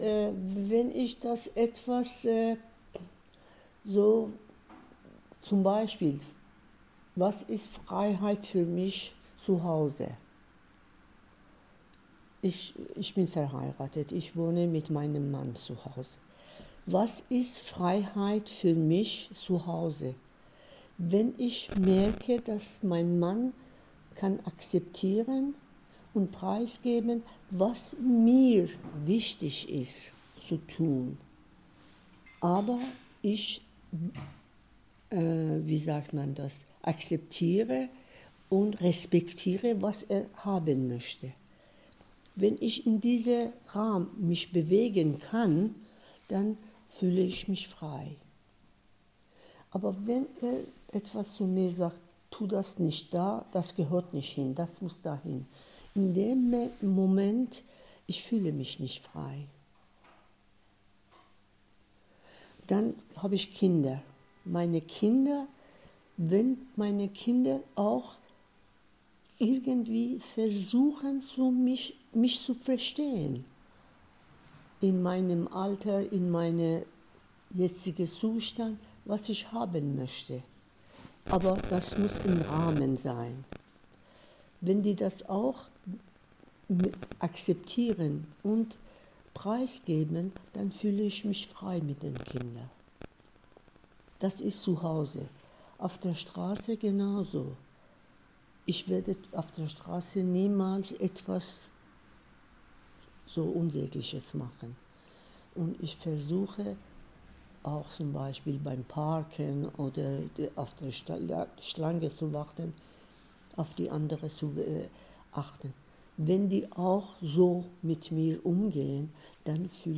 Das Gespräch wurde am 12.02.2025 in Aachen im Rahmen der Veranstaltung "Werkstatt_und_Freiheit - Werkstatt 3. Wir möchten reden" aufgezeichnet.